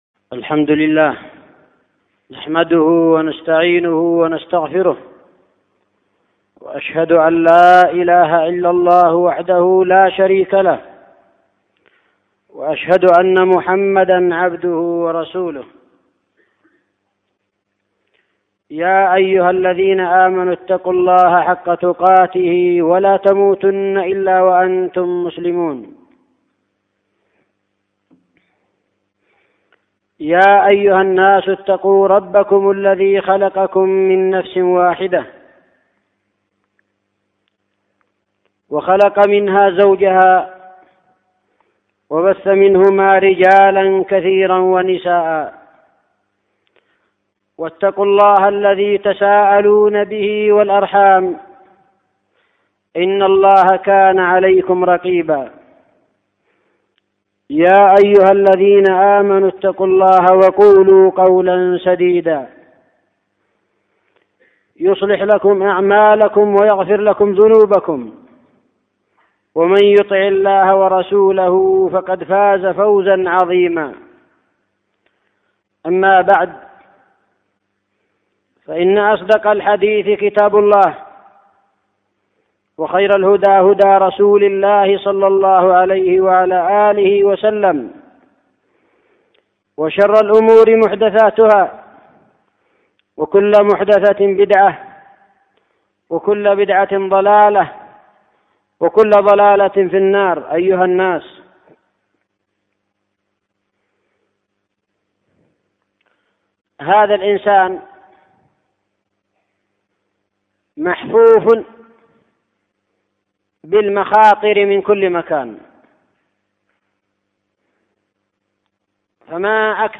خطبة جمعة بعنوان : (( الحصن الحصين من الفتن والشياطين))